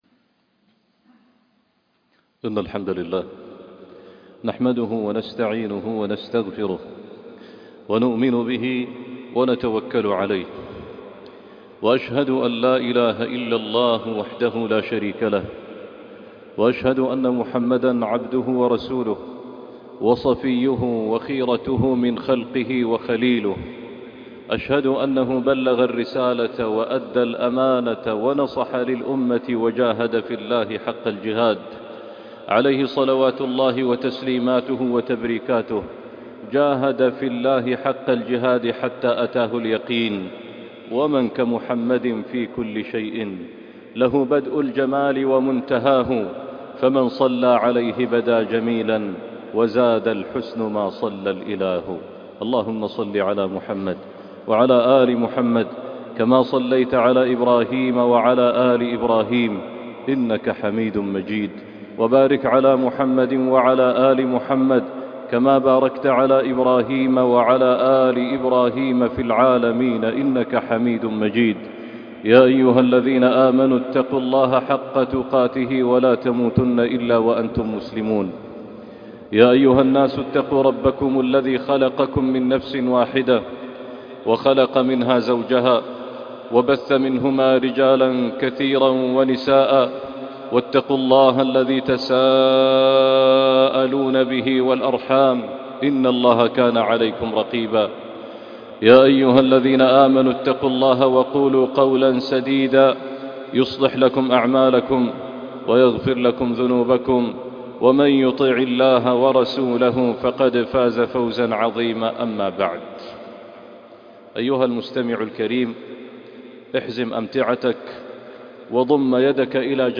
اللحظة الفارقة - خطبة الجمعة